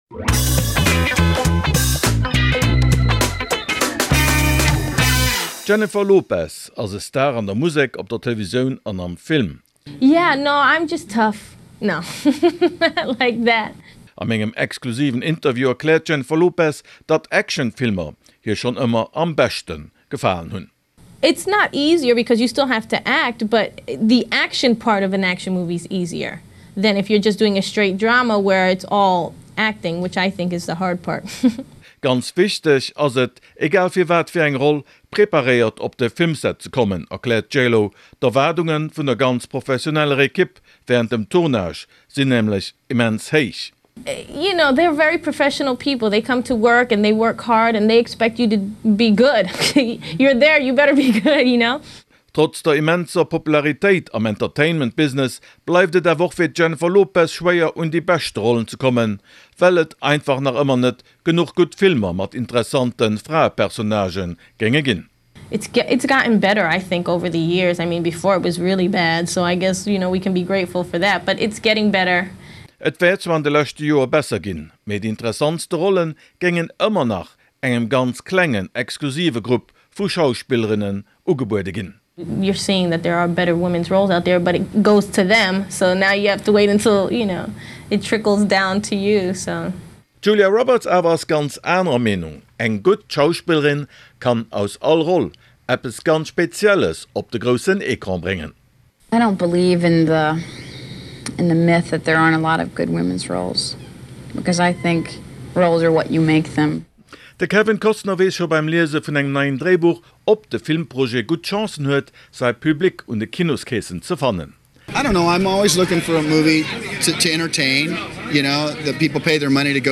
Costner, Dustin Hoffman oder Jennifer Lopez am Interview.